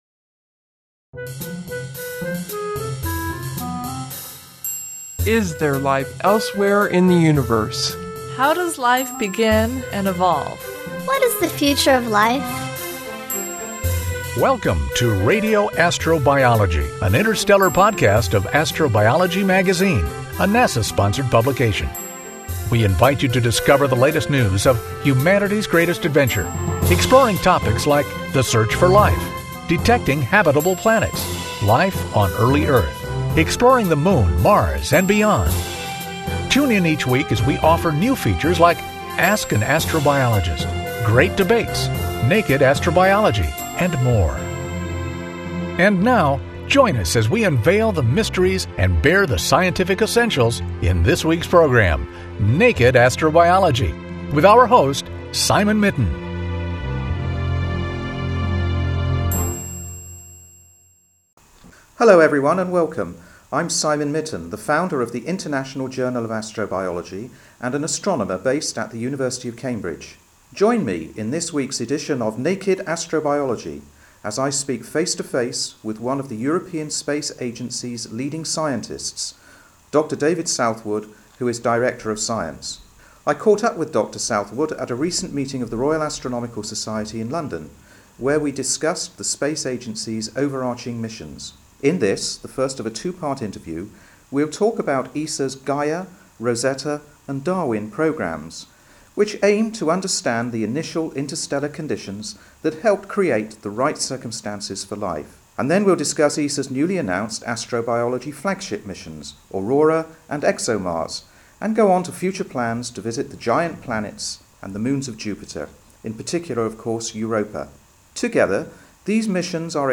entrevista a fondo